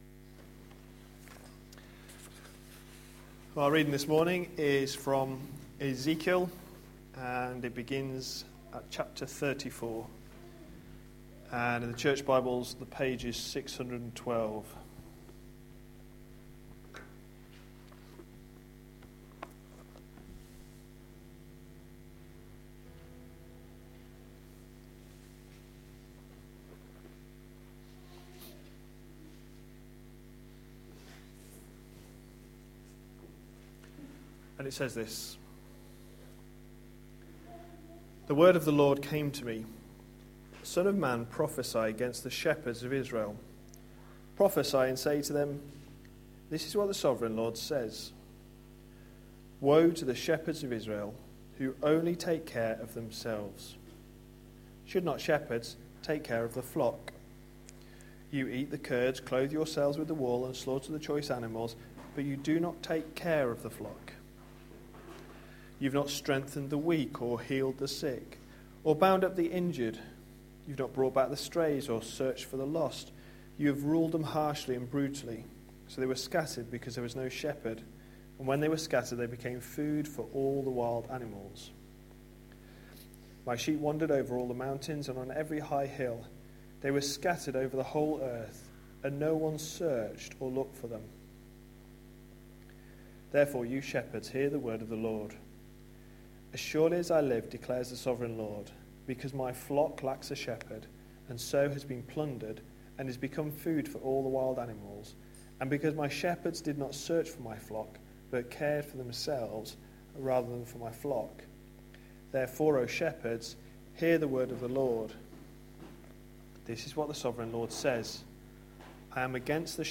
A sermon preached on 10th August, 2014, as part of our Ezekiel series.